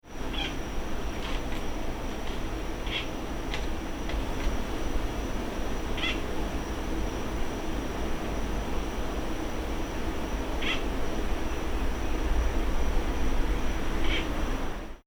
Sounds of Mediterranean Gecko - Hemidactylus turcicus
Males Mediterranean House Geckos make mouse-like squeaking sounds during territorial disputes with other males, and possibly just to announce that they are in possession of a certain territory or female. Males also make a series of clicking sounds to advertise their presence to females during the breeding season.
These are two different 15 second recordings of gecko chirps recorded at night in June in Travis County, Texas. The gecko was hidden in a crevice in a garage and was not seen making the calls, but it was regularly seen at this location.
Insects, a White-winged Dove, and the usual urban drone are heard in the background.